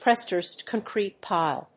prestressed+concrete+pile.mp3